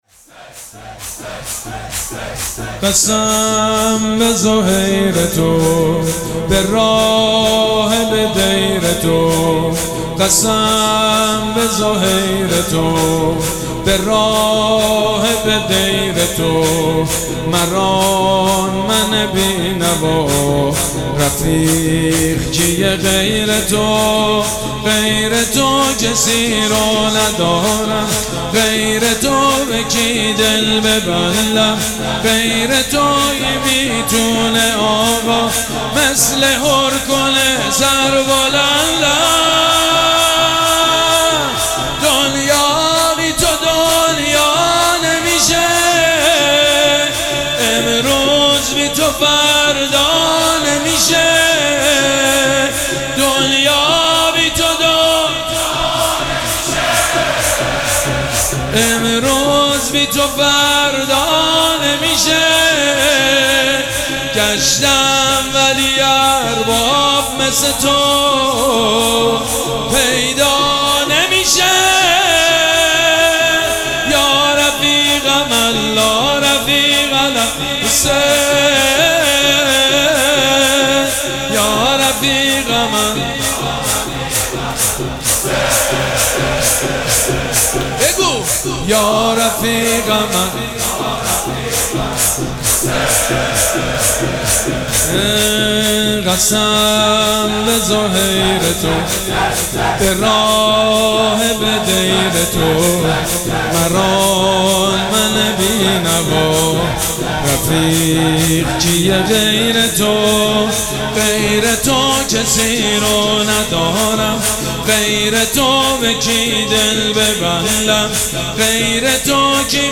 مراسم عزاداری شب هفتم محرم الحرام ۱۴۴۷
شور
حاج سید مجید بنی فاطمه